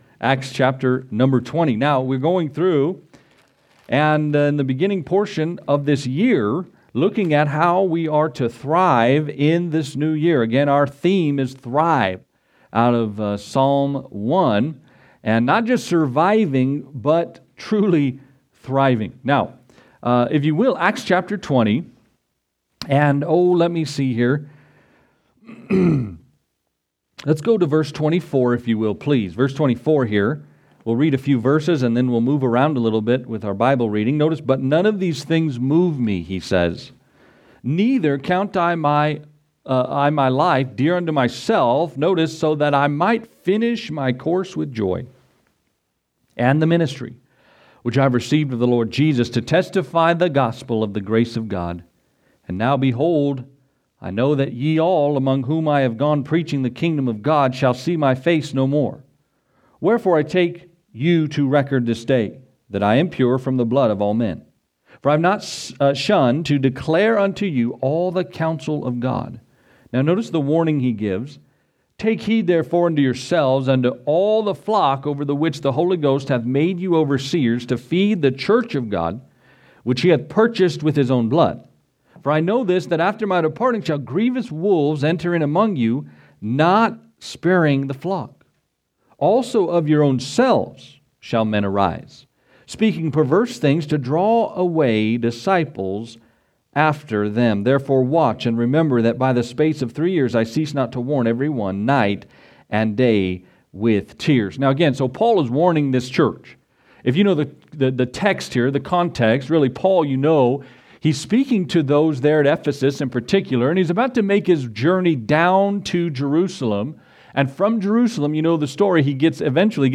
Thrive (Part 5) – Faithway Fellowship Baptist Church